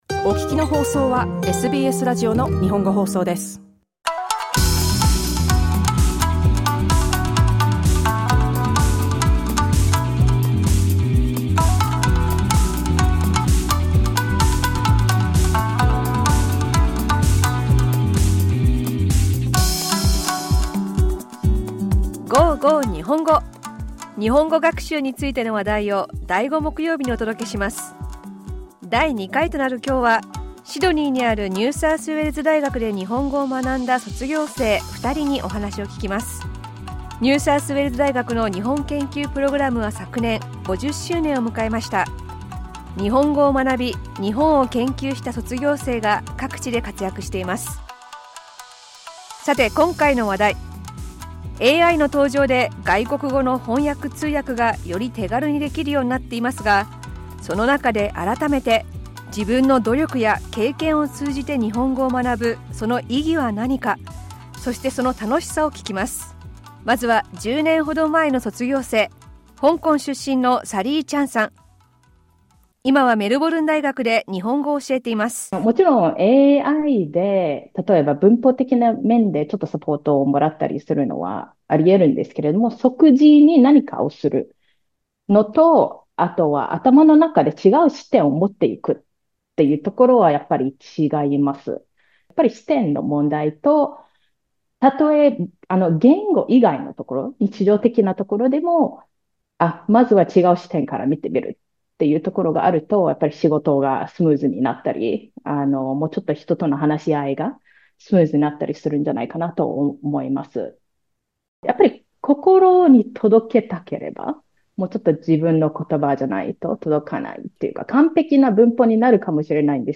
The second episode of ‘Go Go Nihongo!’ featured two graduates of the Japanese Studies at the University of New South Wales. While AI making interpretation and translation more accessible, we heard about the significance and enjoyment of learning Japanese through their own efforts and experiences.